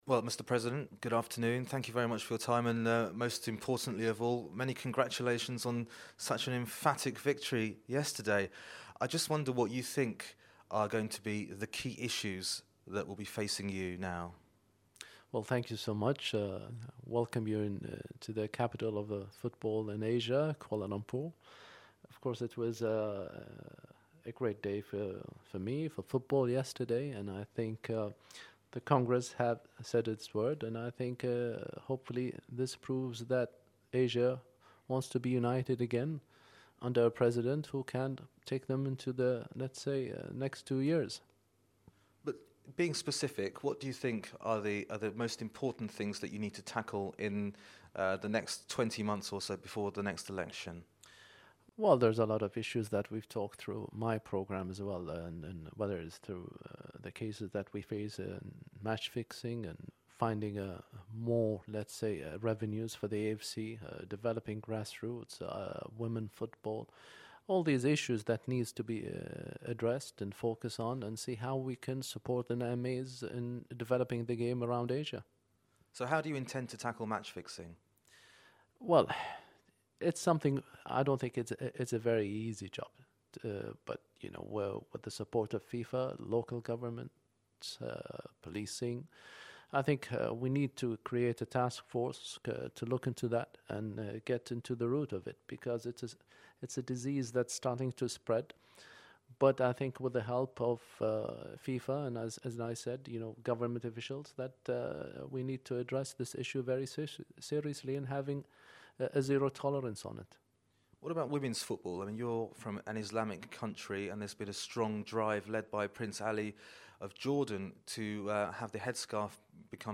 My interview with the new President of the Asian Football Confederation, Sheikh Salman Bin Ibrahim Al-Khalifa of Bahrain. 03/05/12